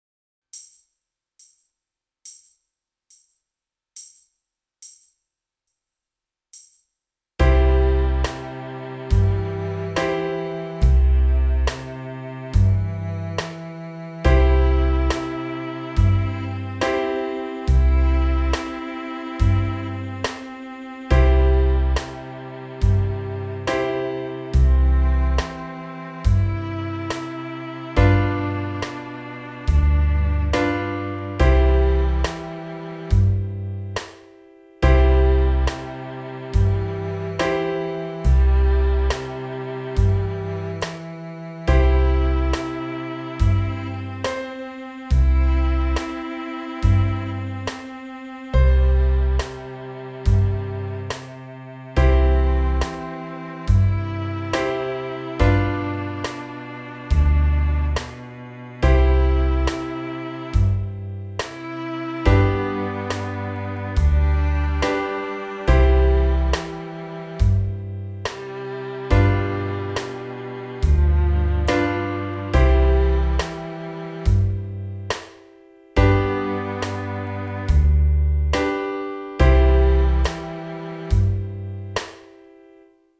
Soundbeispiel – Melodie & Band sowie Band alleine: